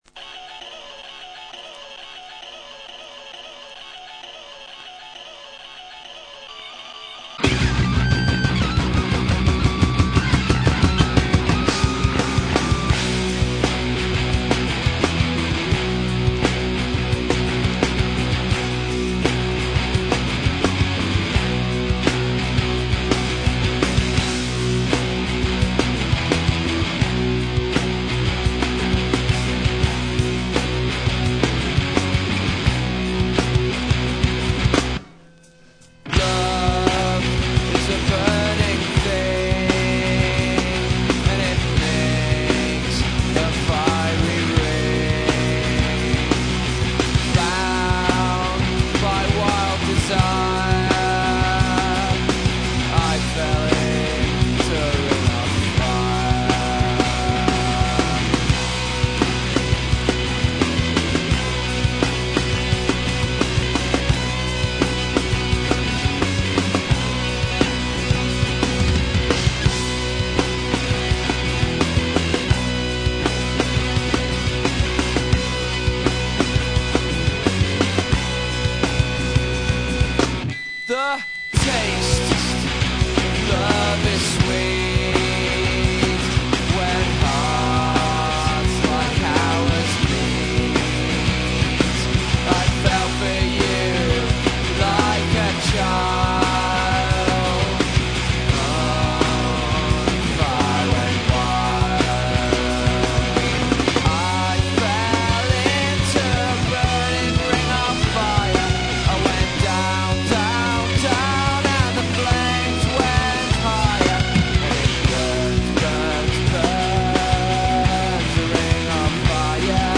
Je to taková bigbítová pocta již nežijícímu muzikantovi.